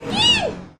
fail_panda.ogg